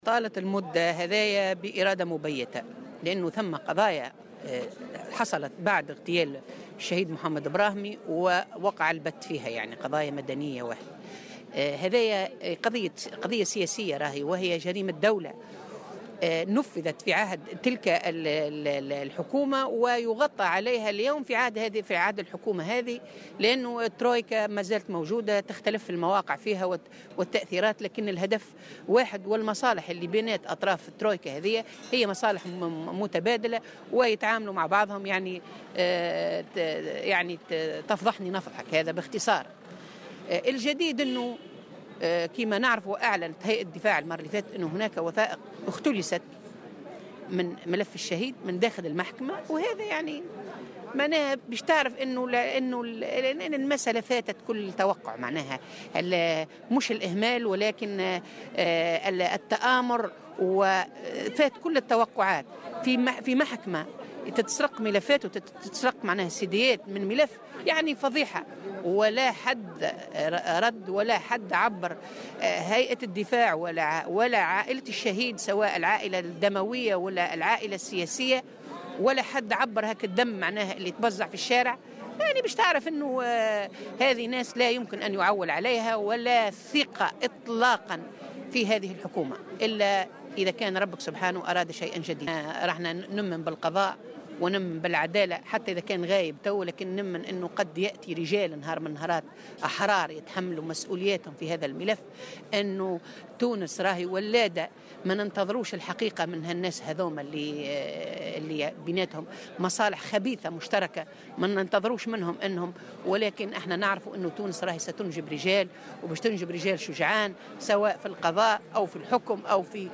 وأضافت في تصريح لمراسل "الجوهرة أف أم" أنه تم اختلاس وثائق من ملف قضية البراهمي من داخل المحكمة، واصفة ذلك بـ"الفضيحة".وقالت عواينية : " لا ننتظر الحقيقة من أطراف تجمعهم مصالح خبيثة...ولكننا لن نيأس".